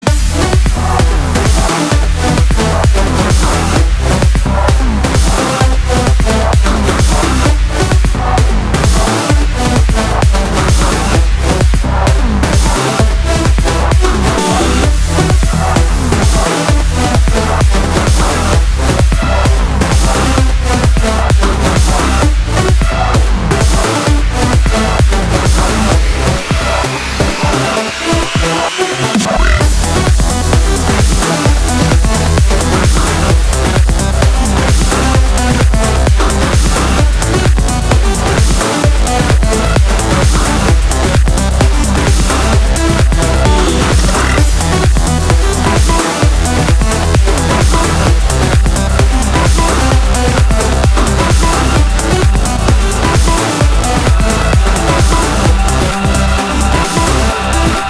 • Качество: 320, Stereo
громкие
зажигательные
заводные
электронная музыка
без слов
Жанр: Trance